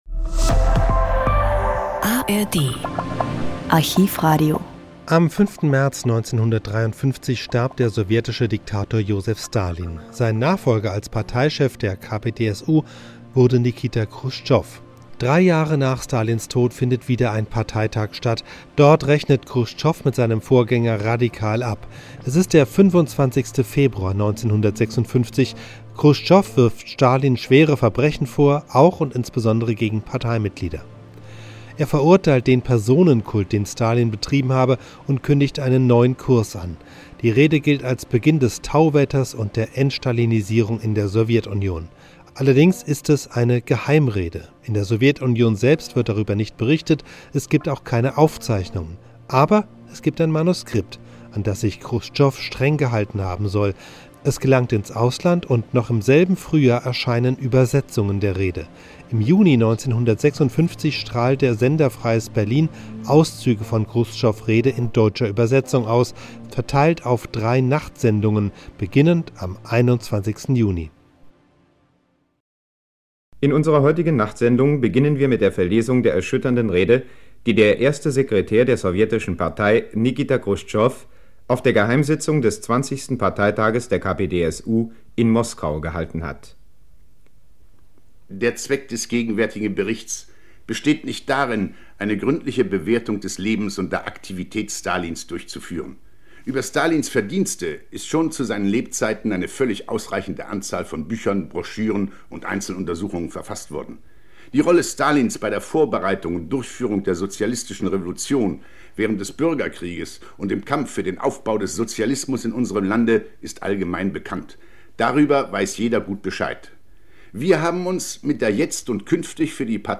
Im Juni 1956 strahlt der Sender Freies Berlin Auszüge von Chruschtschows Rede in deutscher Übersetzung aus, verteilt auf drei Nachtsendungen, beginnend am 21. Juni.